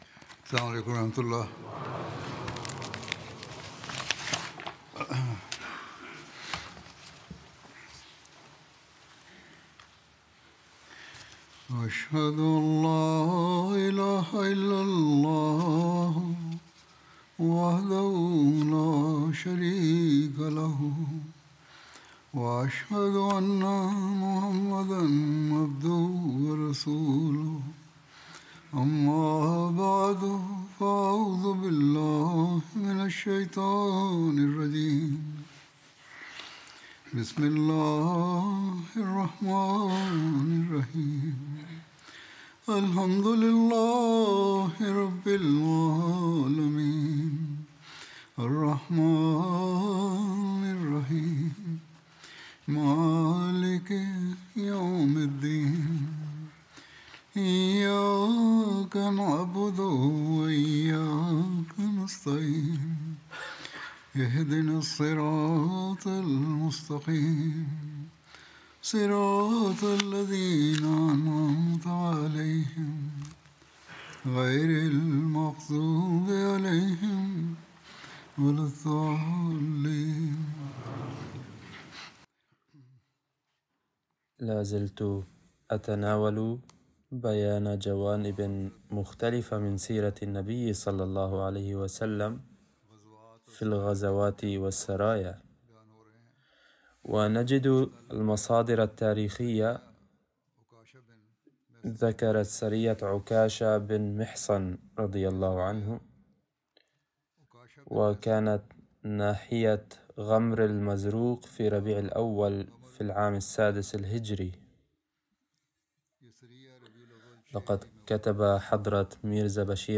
Arabic Translation of Friday Sermon delivered by Khalifatul Masih